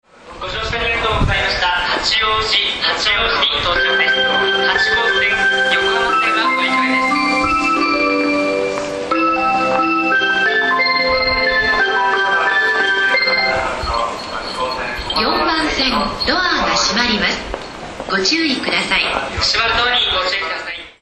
全ホーム旋律は同じですがアレンジが異なります。